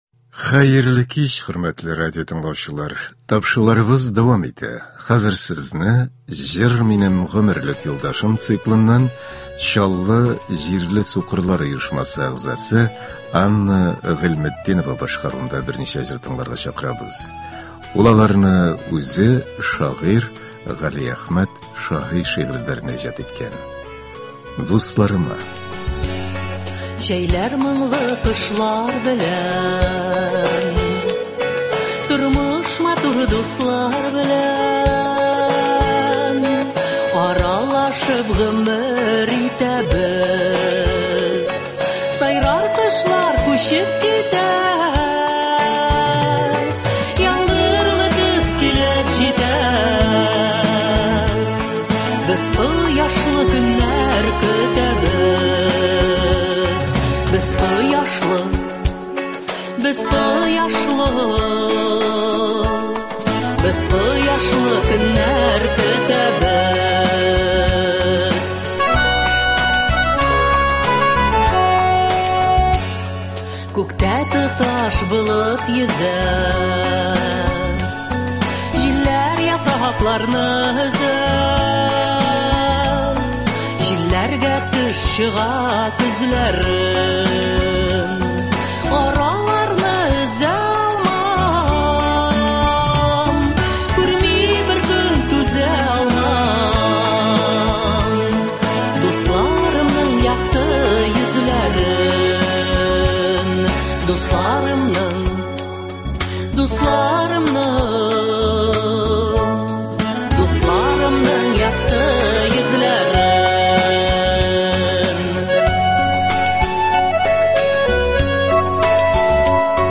Концерт (08.08.22)